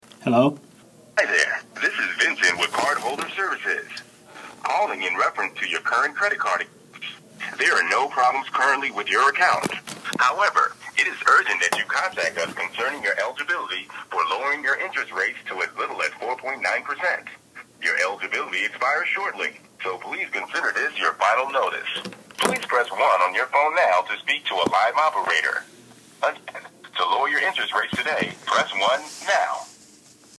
The caller tells you there is no current problem with your card, but that you are eligible for a limited-time offer to get a lower rate—for a fee.
RobocallsCreditCard_Scam_01.mp3